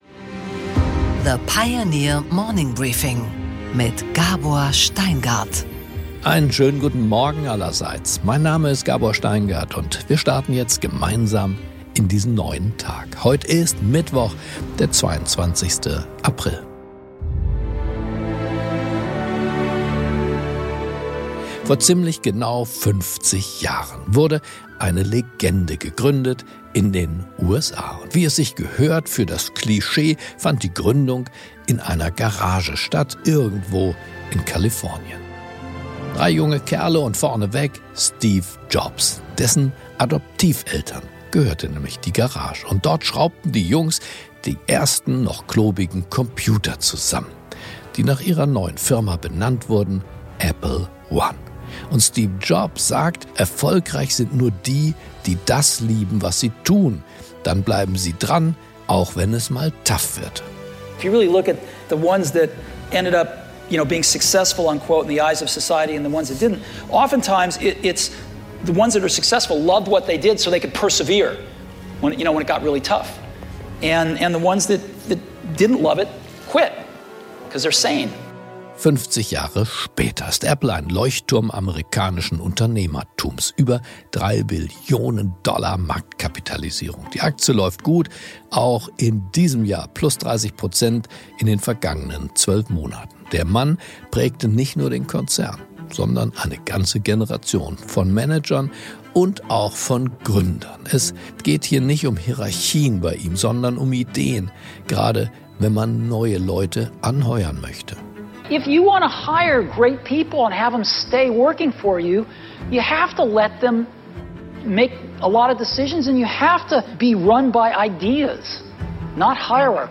Gabor Steingart präsentiert das Morning Briefing.